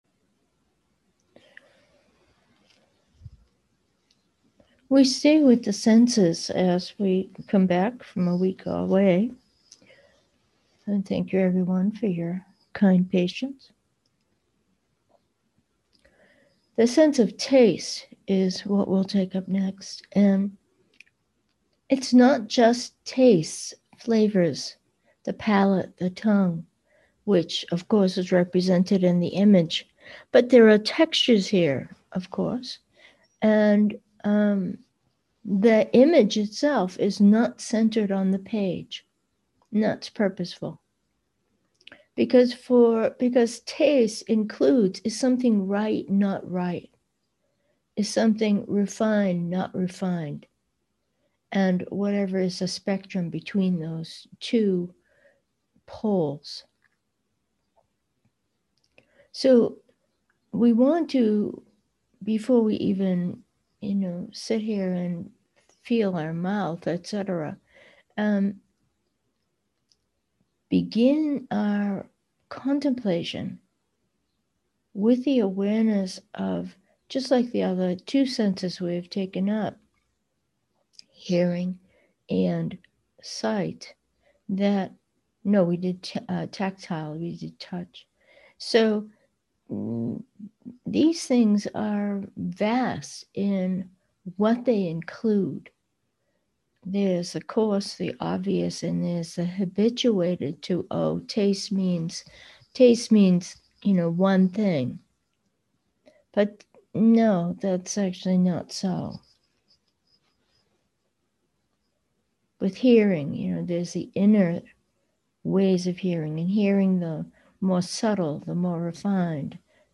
Meditation: taste and awareness 1
To set the tone of discovery for this week via the sense of taste, this meditation session guides us through contemplations on things most often not thought of as the result of taste, but absolutely so. We end up in silent meditation after the guided contemplation.